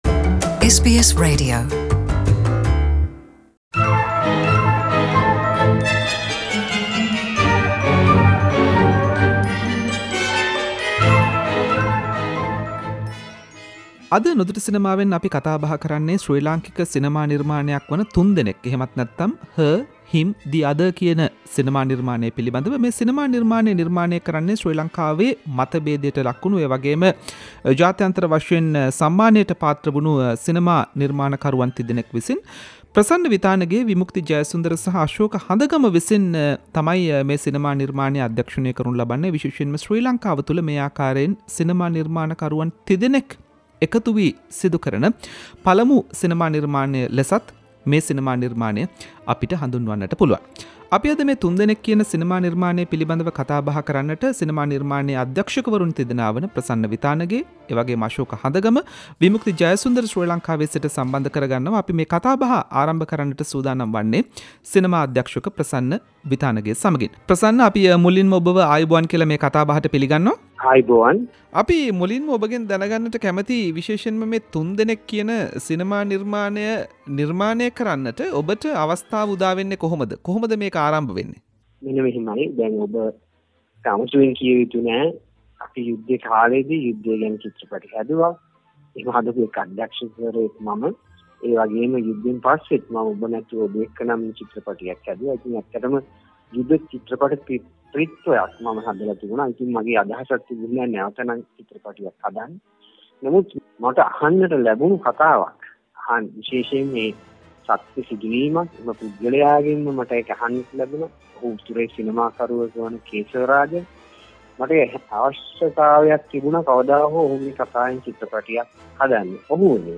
For the first time three Sri Lankan prominent film directors collaborated and directed one film, “Her, Him, the Other”. Prasanna Vithanage, Vimukthi Jayasunadara and Asoka Handagama created this movie based on Sri Lankan post war reconciliation process and three directors joined with SBS Sinhalese monthly Cinema segment to talk about the movie.